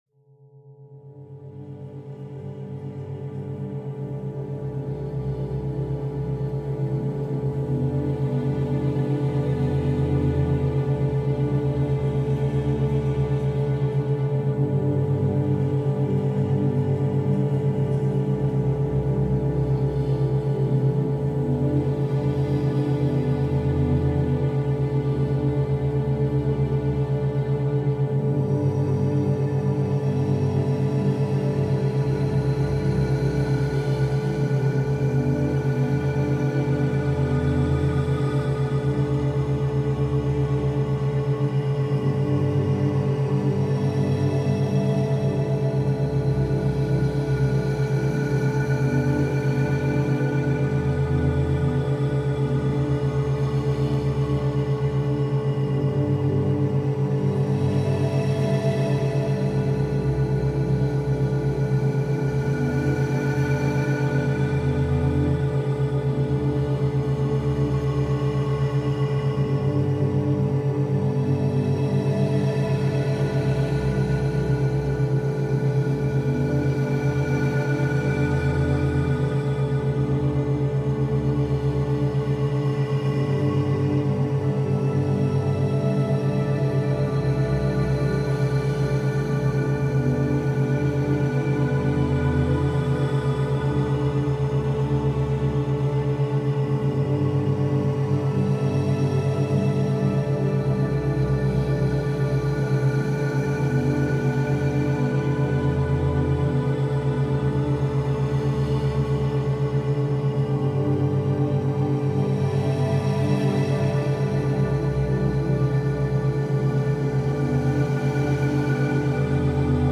Sunset Light Ambience – Calm Study and Reading Atmosphere
Study Sounds, Background Sounds